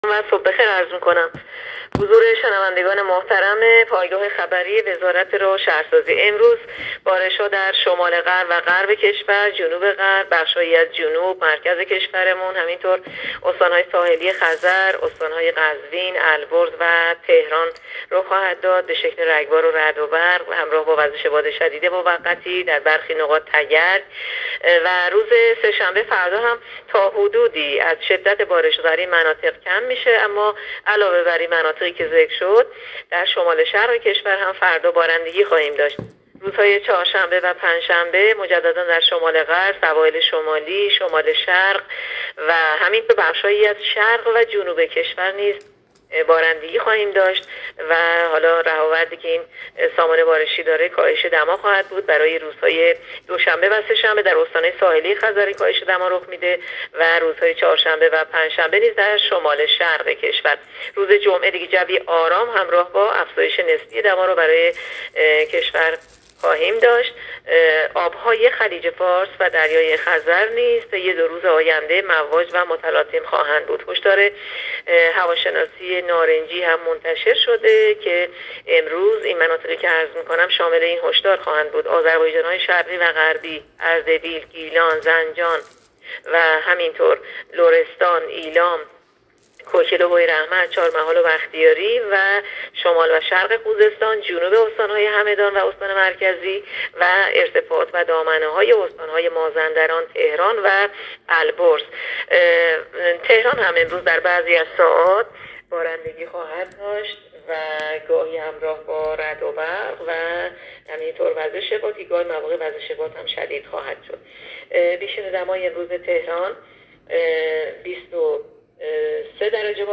گزارش رادیو اینترنتی پایگاه‌ خبری از آخرین وضعیت آب‌وهوای ۱۷ اردیبهشت؛